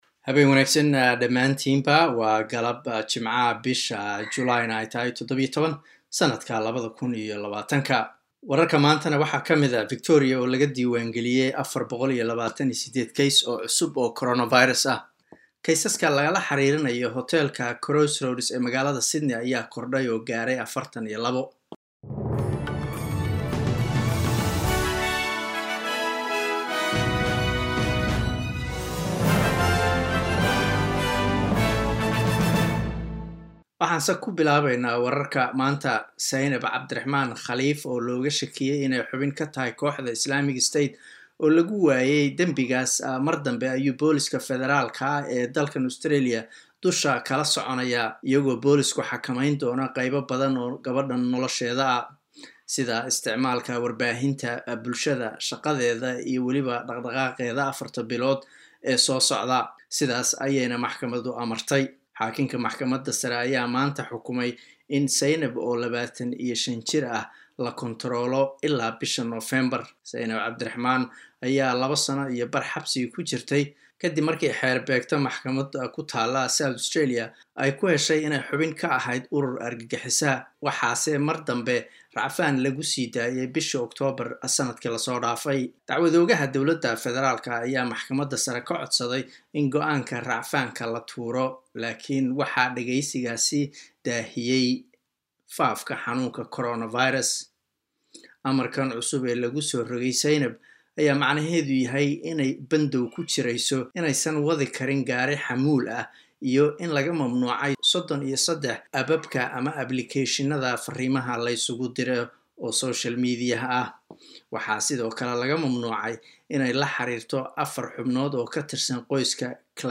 Wararka SBS Somali Jimco 17 July